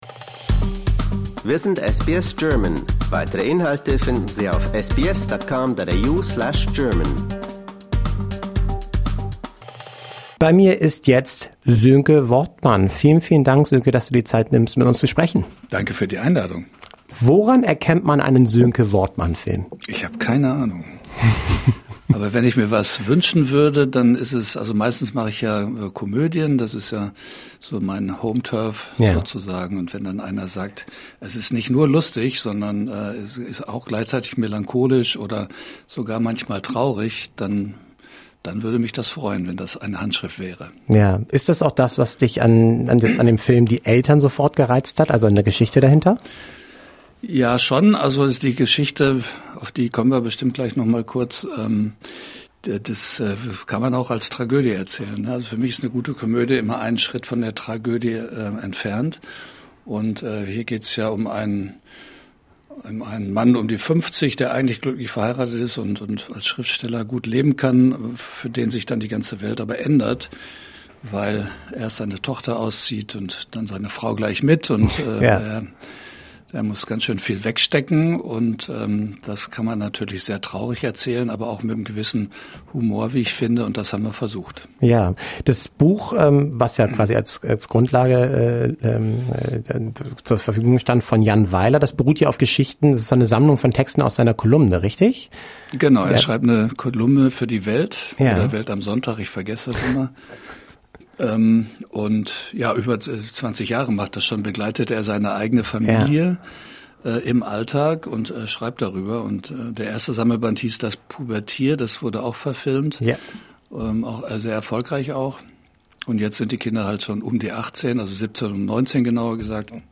zum Gespräch in SBS-Studios in Sydney